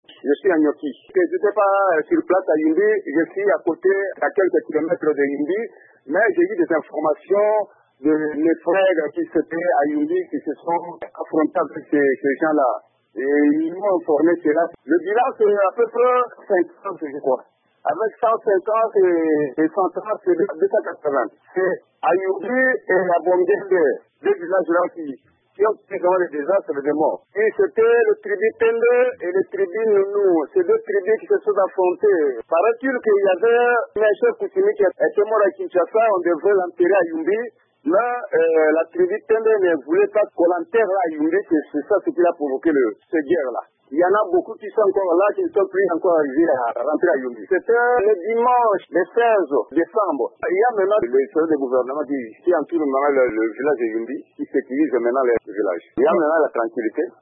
Violence de Yumbi : un habitant témoignage